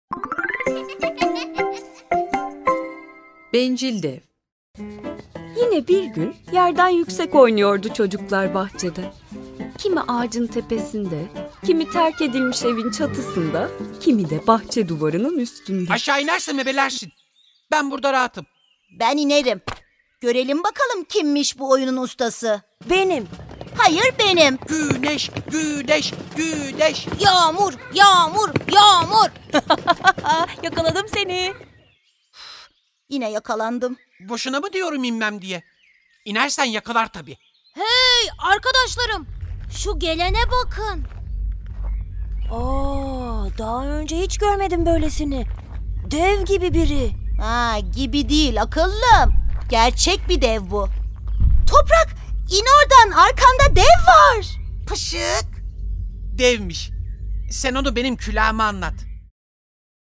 Bencil Dev Tiyatrosu